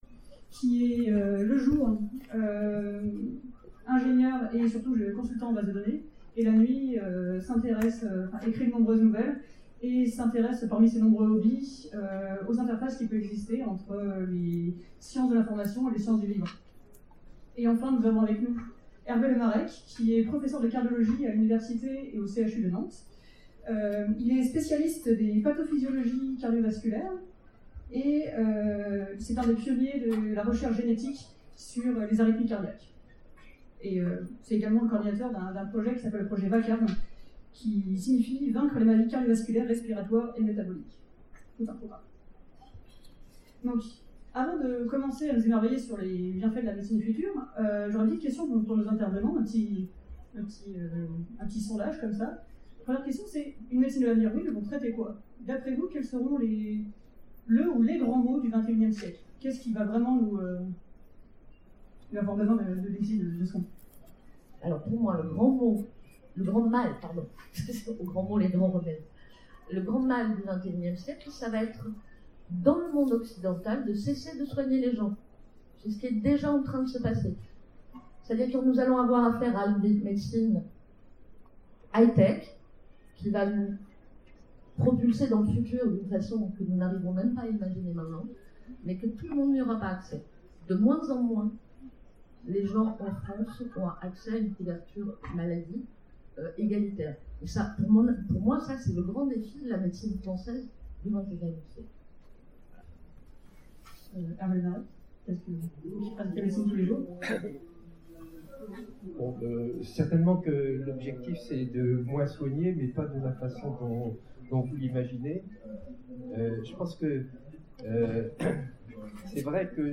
Utopiales 2017 : Conférence La médecine de l’avenir
Utopiales 2017 : Conférence La médecine de l’avenir Télécharger le MP3 La qualité du son s'améliore après trois minutes.